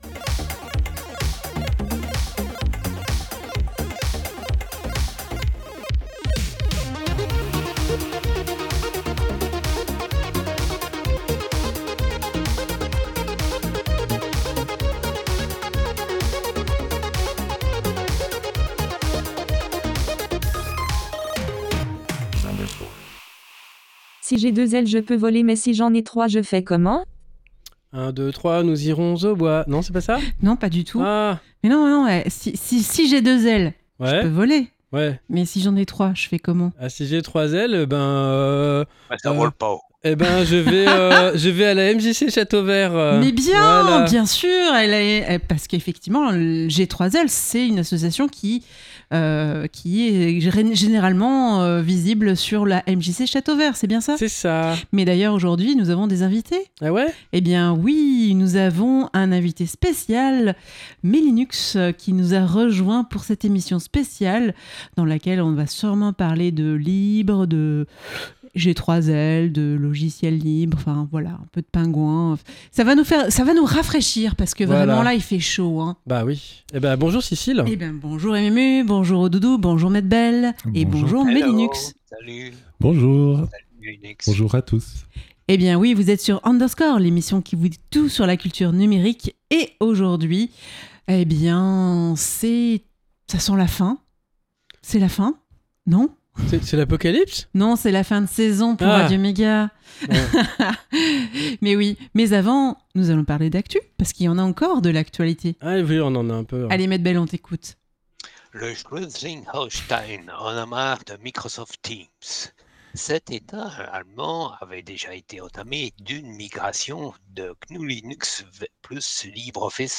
Actu